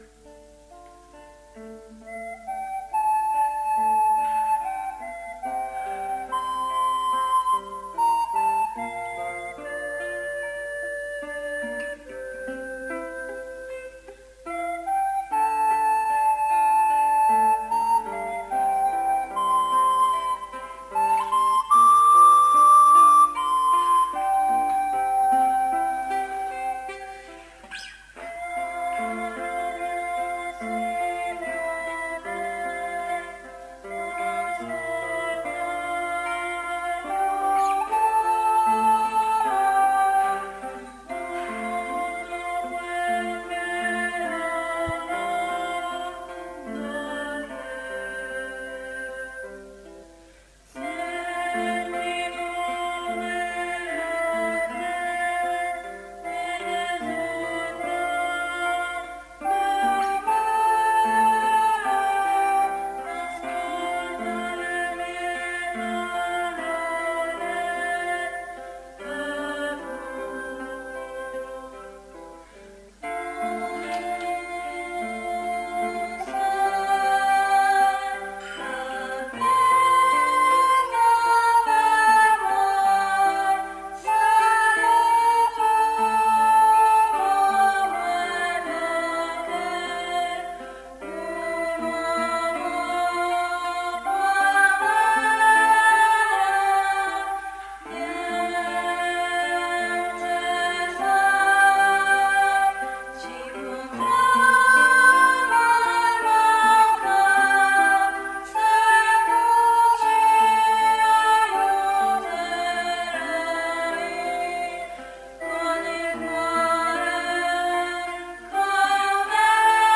Coretto parrocchiale "Les Mariutines" di Tomba